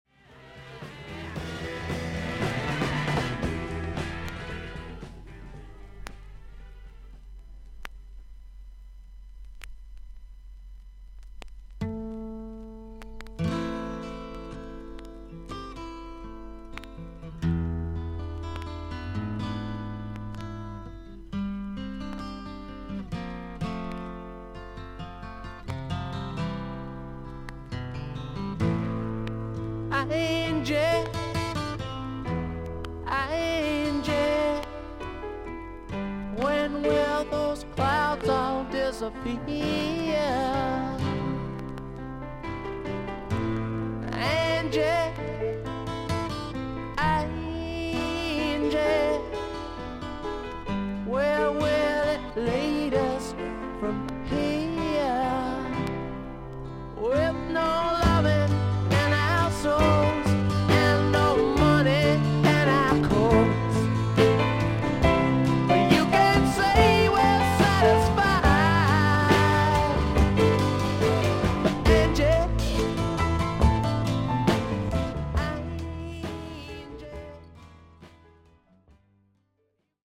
A4終わりからA5序盤に3mmほどキズ、少々軽いノイズあり。
クリアな音です。